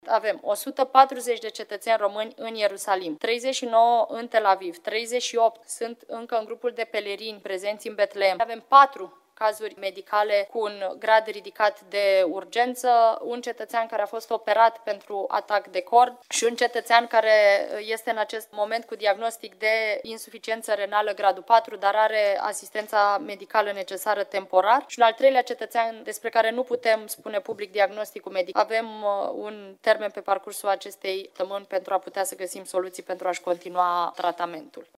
Ministra de Externe, Oana Țoiu: „Este un cetățean care a fost operat pentru atac de cord”